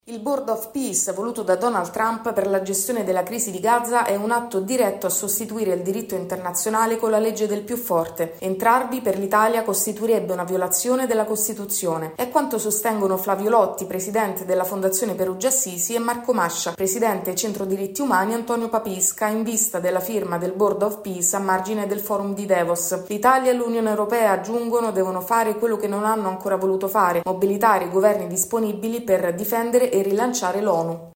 Prevalga la legalità – L’appello delle associazioni pacifiste contro il Board of Peace voluto da Donald Trump per Gaza. Il servizio